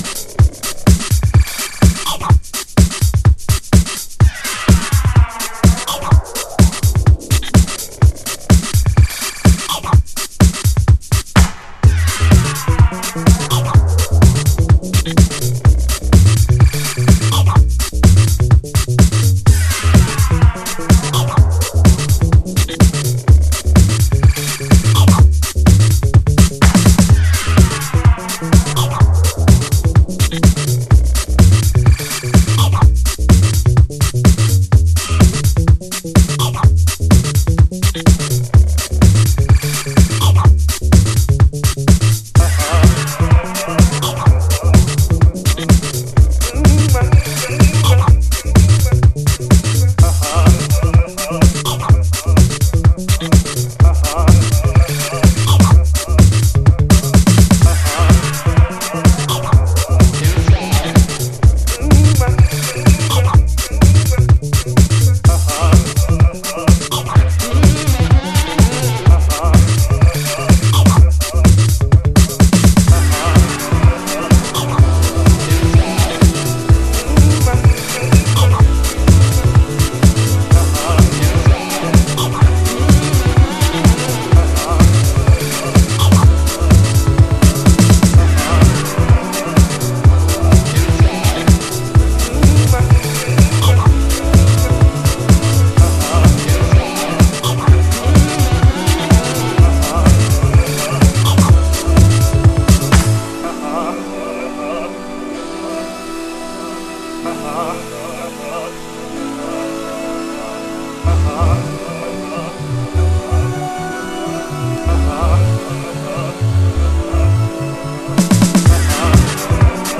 荒めのフィルター使いやバックスピンなどを駆使したナイスブギーグルーヴ。